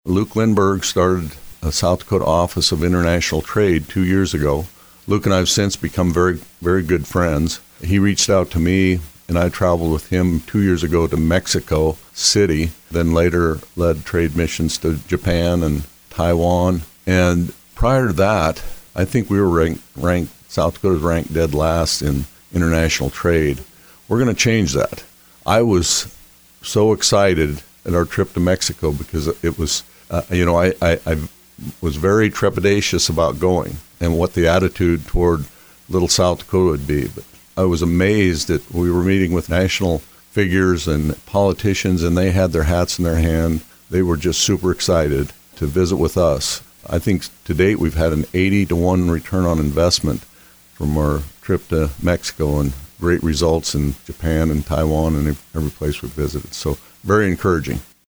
During a stop at the DRG Media Group studios (March 18, 2025), Rhoden said the hope is to bring what towns are doing– or wanting to do– together with what state government is working on in order to access the best economic development opportunities possible.